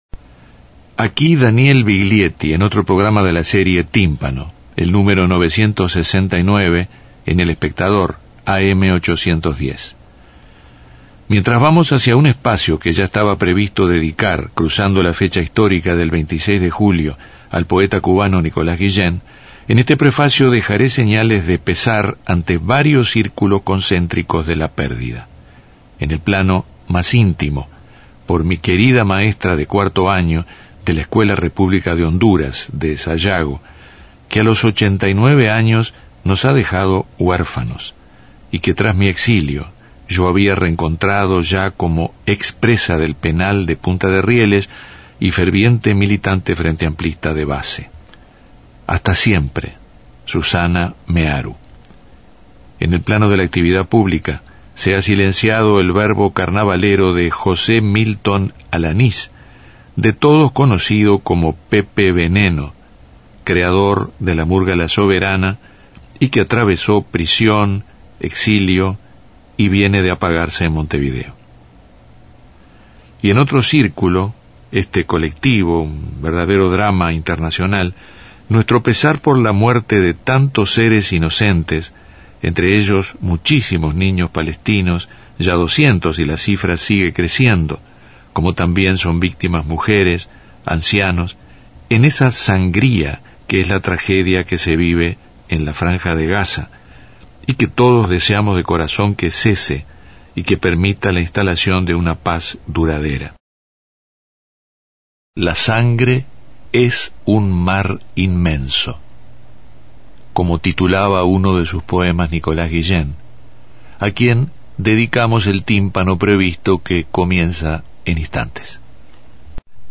En Tímpano poemas en la voz de su autor y algunas músicas que han abordado sus poesías.